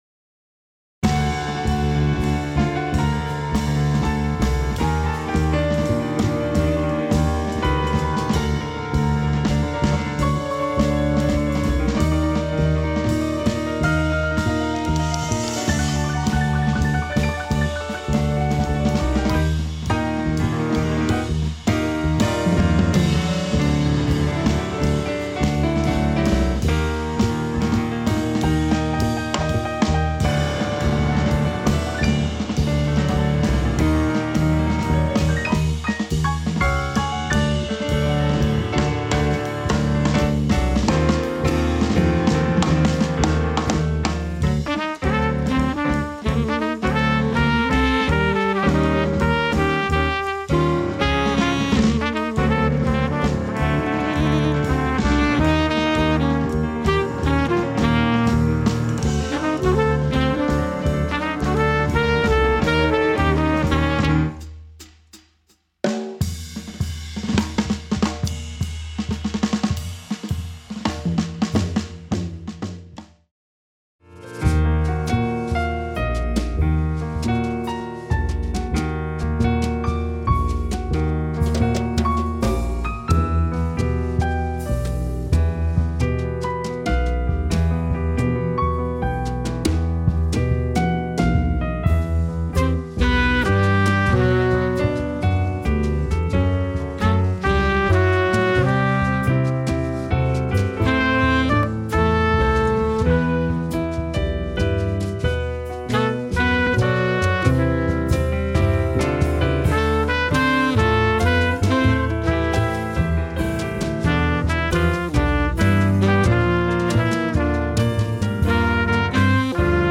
Melodisk jazz med känsla och energi
Varierat och lättlyssnat, alltid med publiken i fokus.
• Jazzband